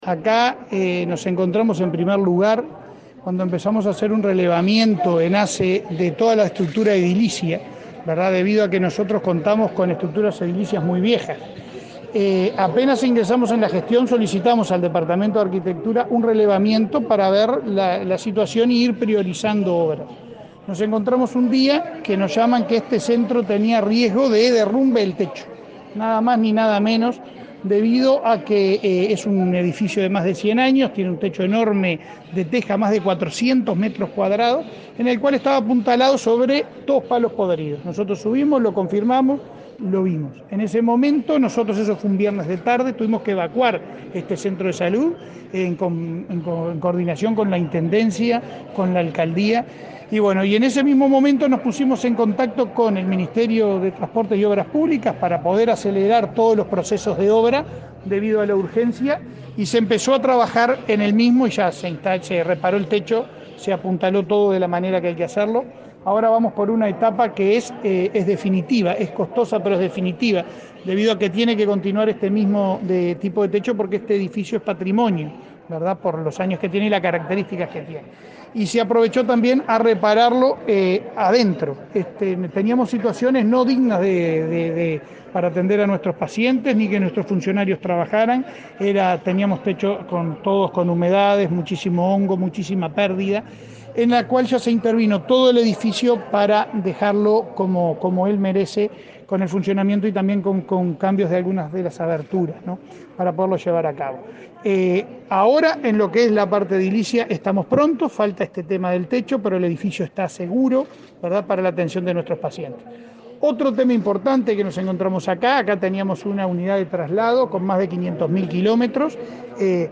Declaraciones del presidente de ASSE a la prensa